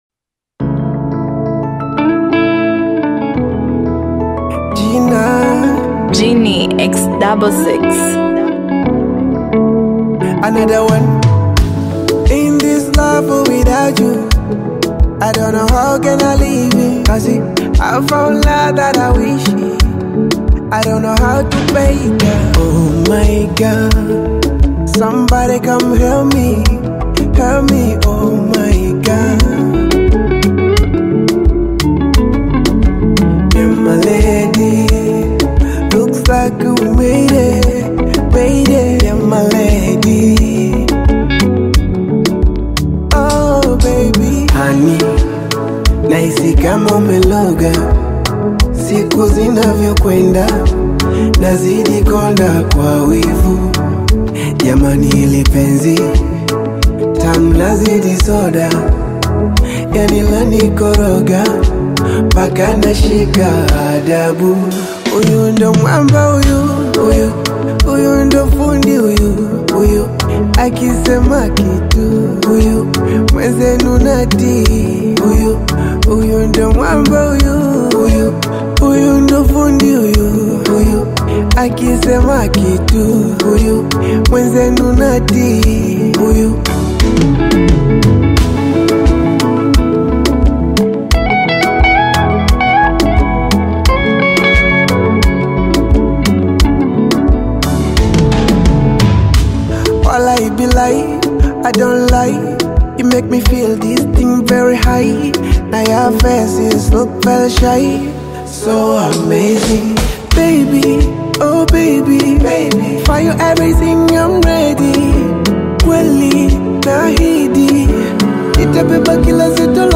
smooth Afro-fusion single
Genre: Bongo Flava